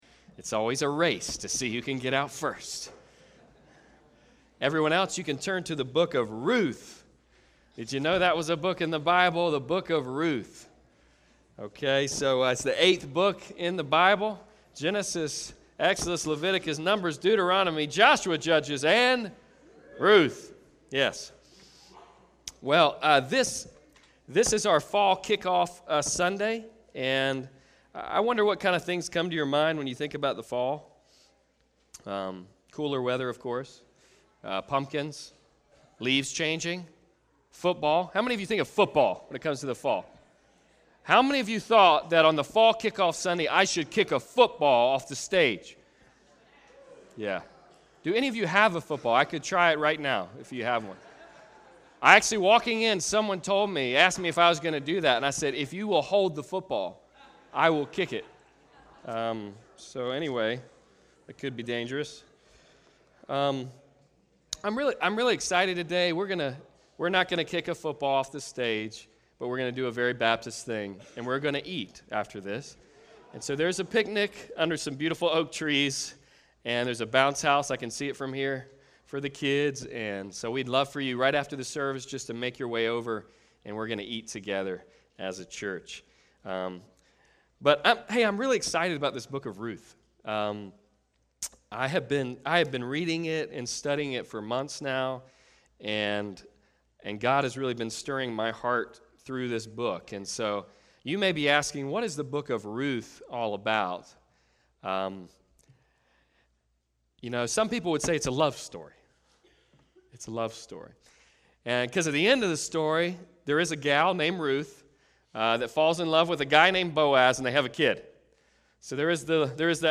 Hope in the Dark Passage: Ruth 1: 1-5 Service Type: Sunday Service « Where is God Taking Us?